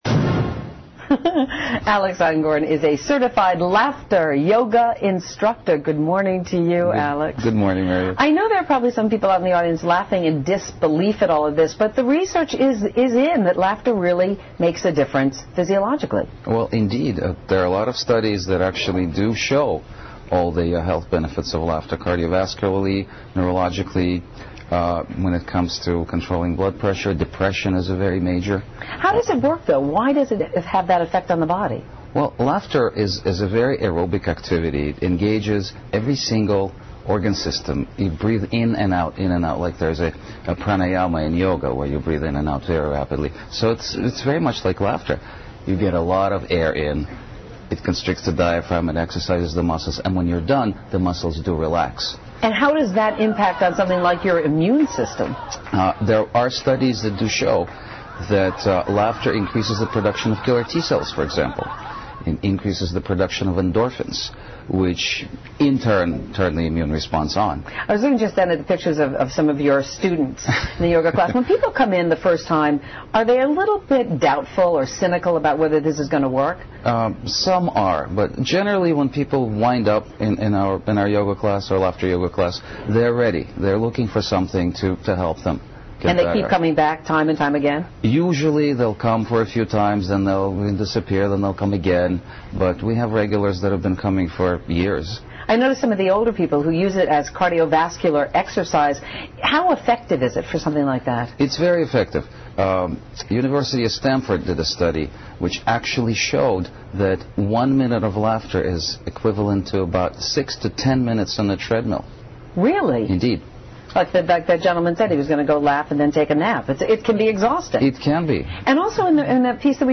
访谈录 Interview 2007-04-21&23, 笑一笑，十年少 听力文件下载—在线英语听力室